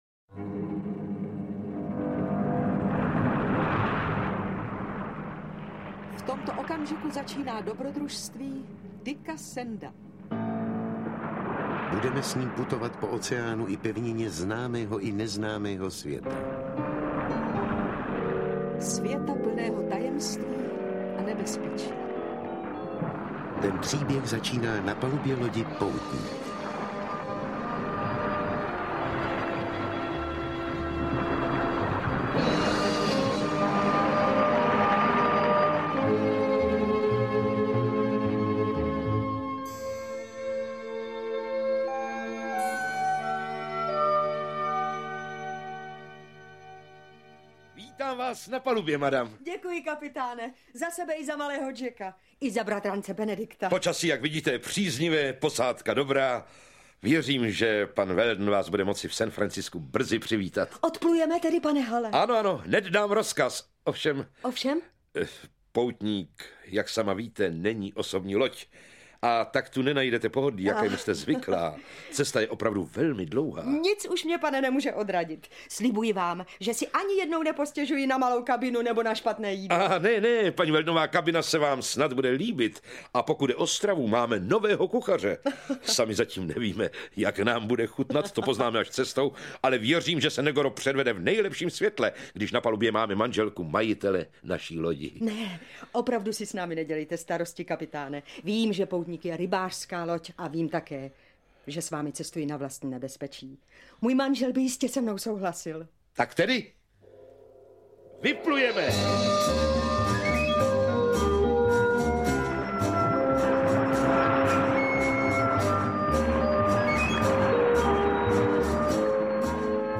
Patnáctiletý kapitán, Ocelové město, Tajemný hrad v Karpatech - Jules Verne - Audiokniha